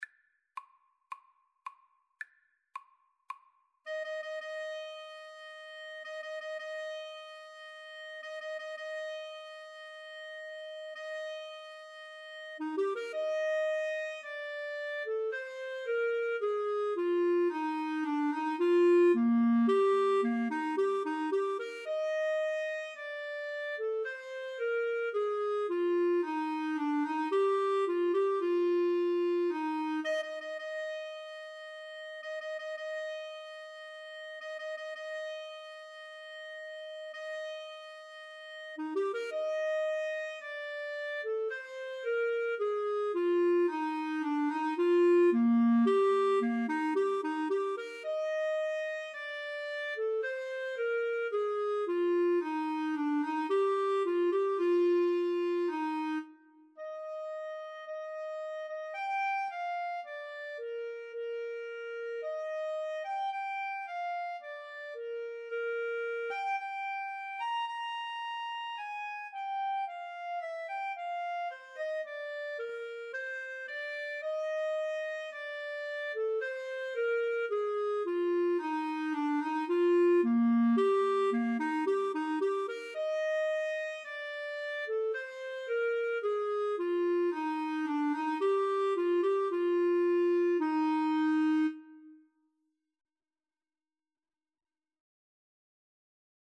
ClarinetAlto Saxophone
Andante maestoso =c.110 =110